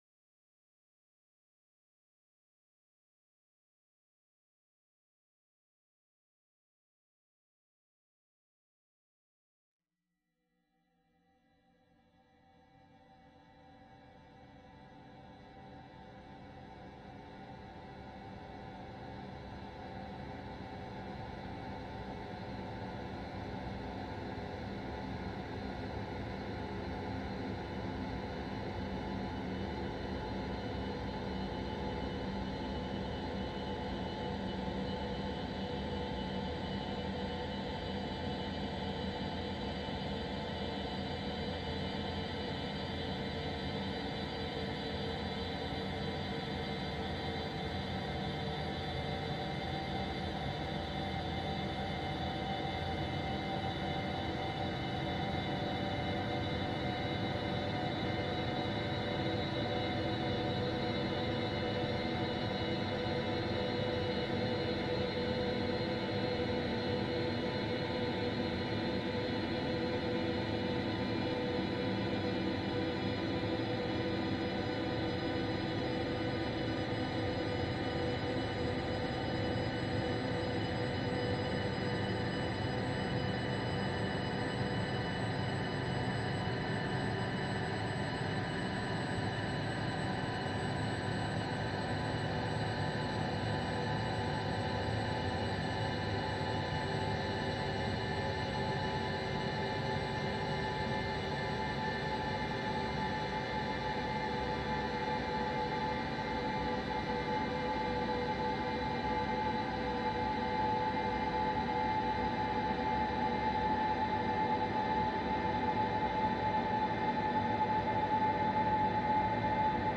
an excerpt from a recent improvised performance
tenor saxophone